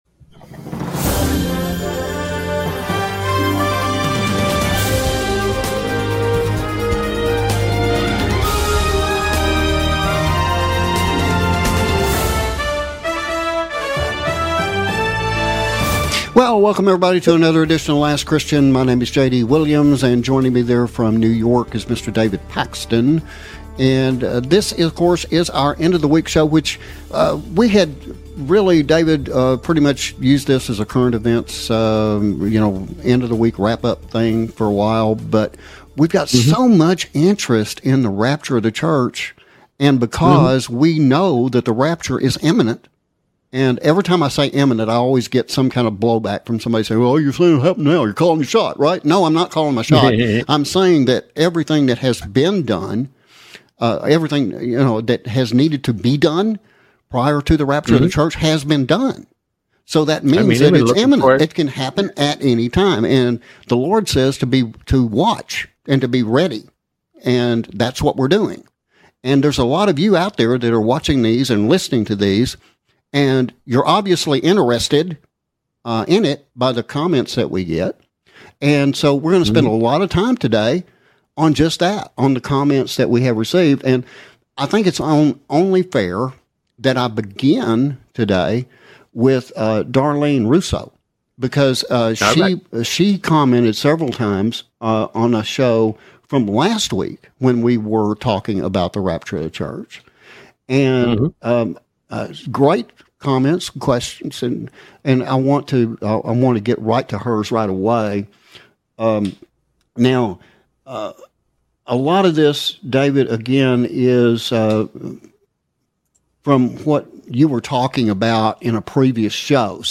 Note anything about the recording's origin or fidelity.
All included are News Segments collected from around the World which indicate Time is indeed short before the Rapture of the Church.